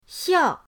xiao4.mp3